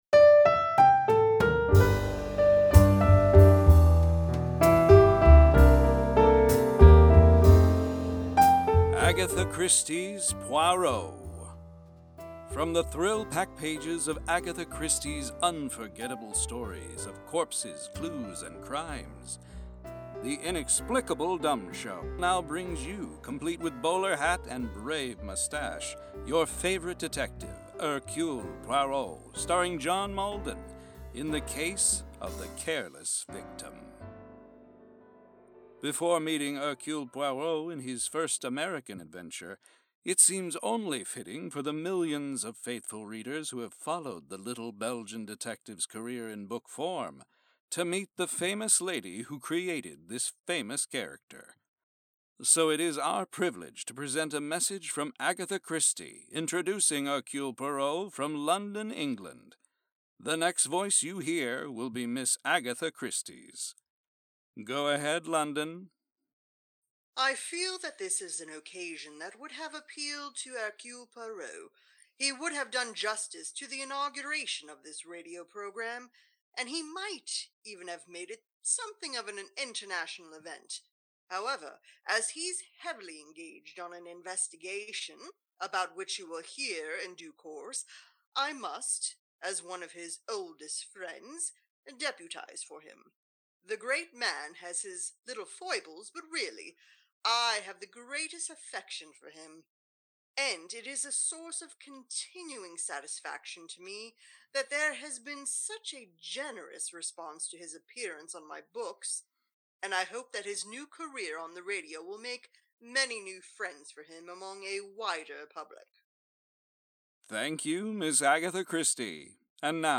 For those that don’t know; during the summer, the IDS recreates old radio programs to the best of our abilities for your entertainment and our amusement.